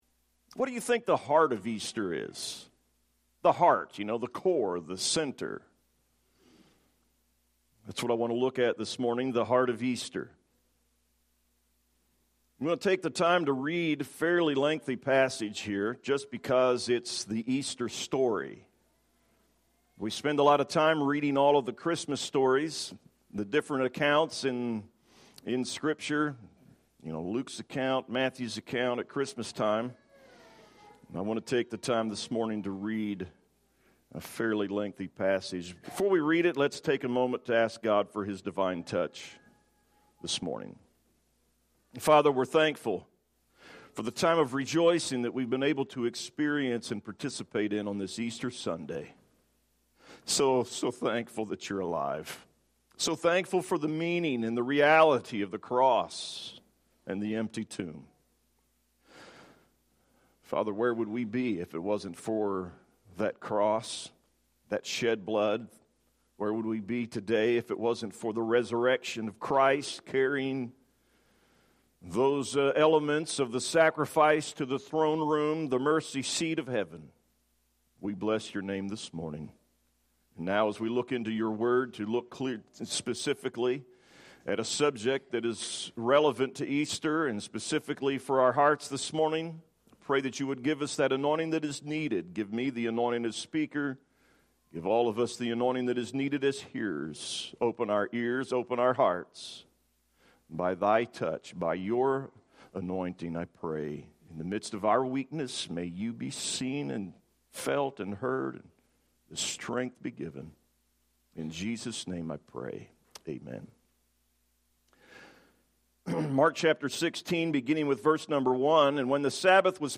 Easter